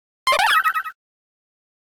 Archivo:Grito de Mime Jr..ogg
== Licencia == {{Archivo de audio}} Categoría:Gritos de Pokémon de la cuarta generación